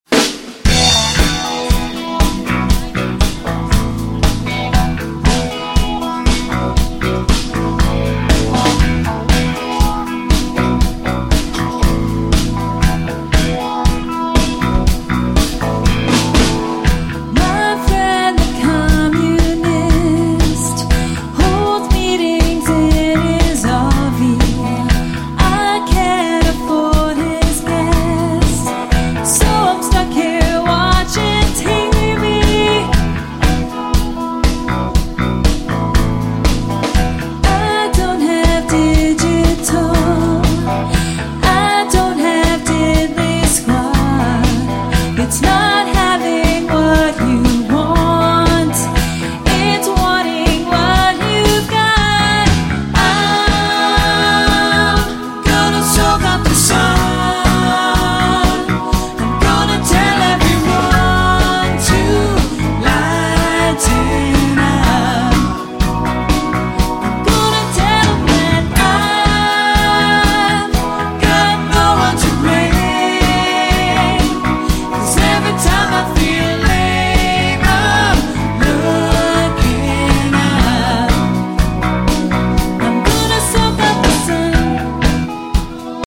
four piece group